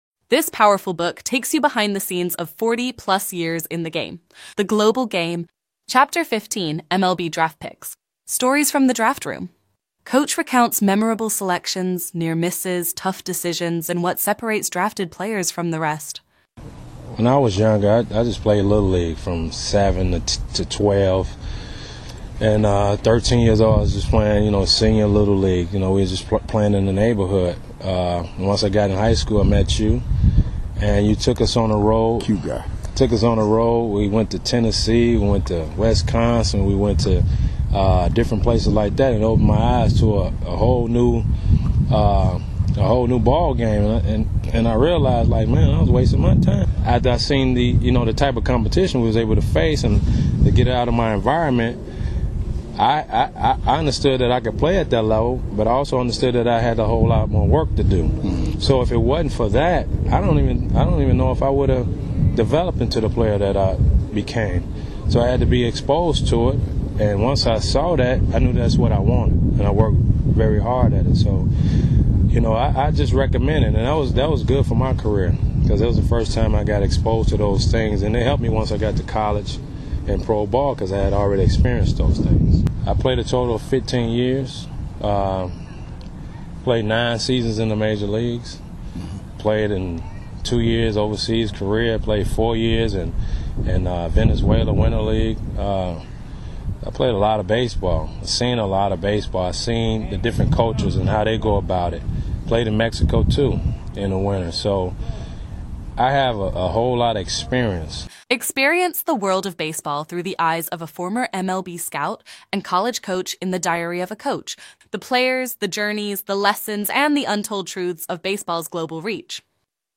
Stories from the draft room. Coach recounts memorable selections, near misses, tough decisions, and what separates drafted players from the rest.